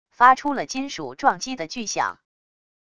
发出了金属撞击的巨响wav音频